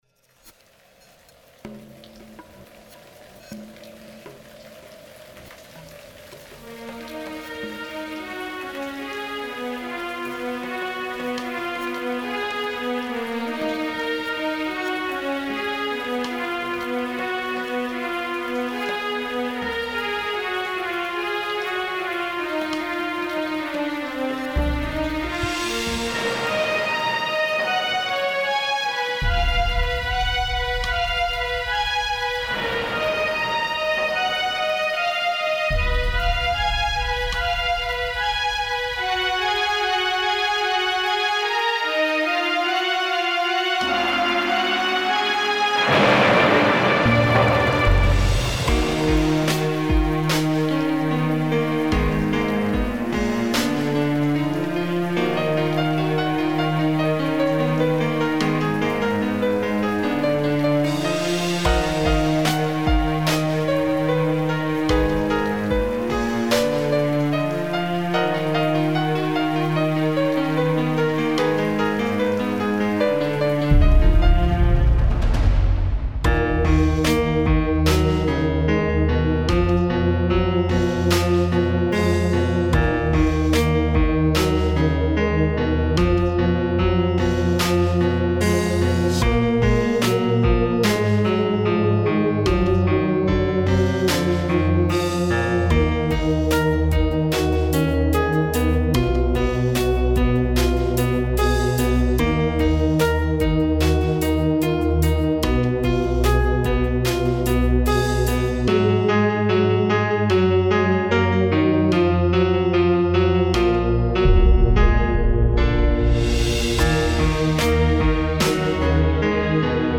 Сэмплы: сэмплы сделаны в EJ D6 и FL8